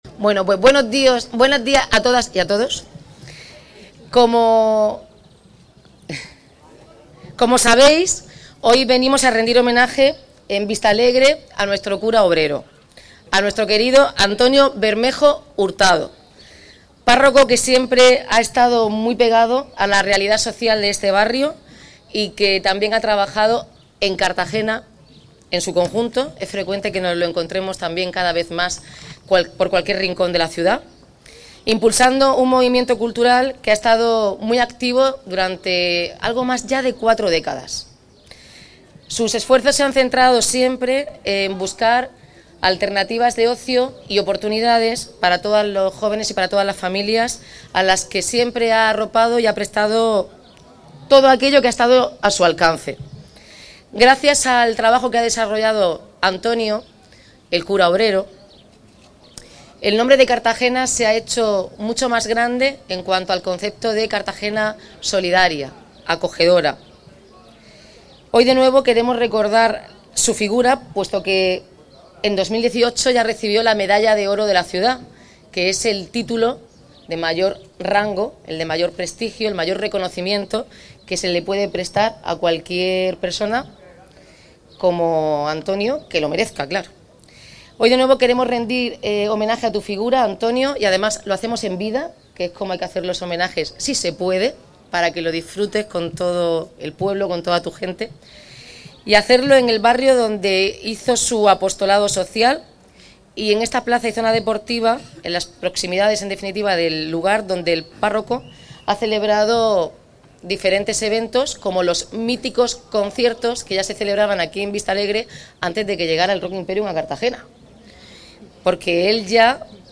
Aprovechando la celebración de la Semana Cultural ha tenido lugar este domingo, 2 junio, un homenaje al querido párroco.